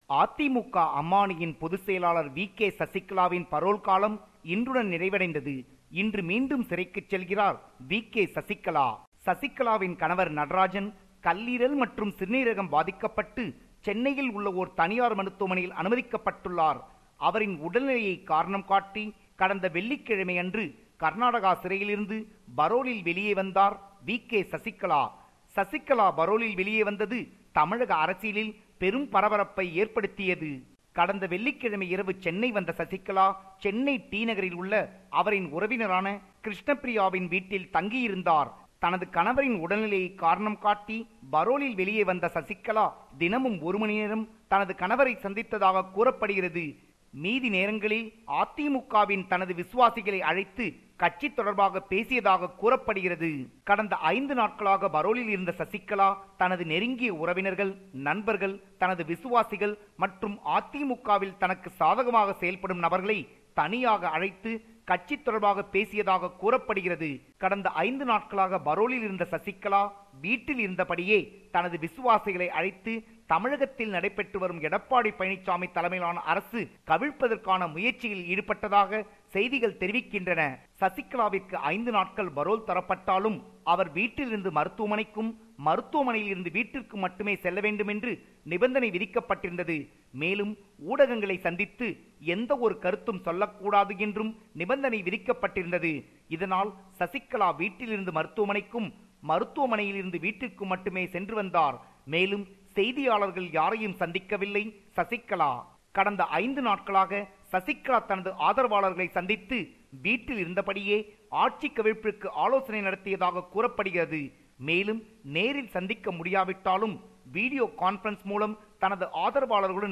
Tamil News